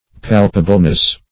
[1913 Webster] -- Pal"pa*ble*ness, n. -- Pal"pa*bly,